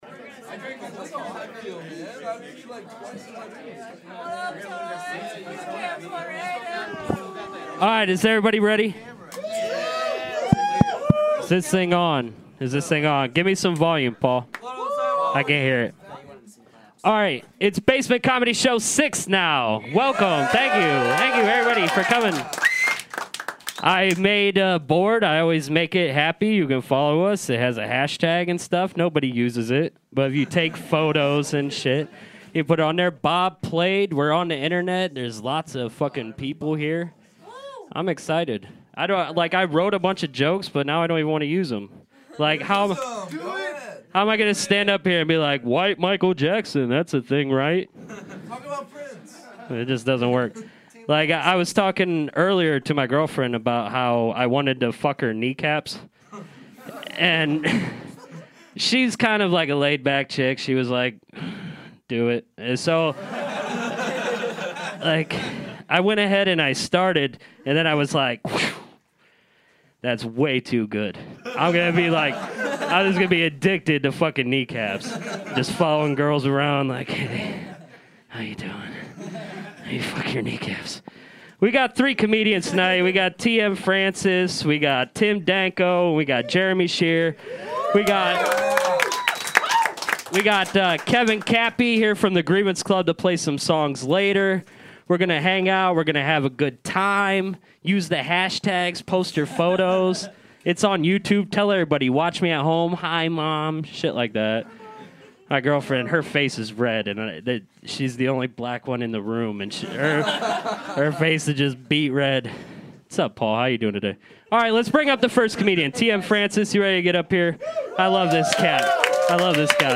On April 23rd 2016 Lousy Weather Media hosted it’s 6th Basement Comedy Show featuring: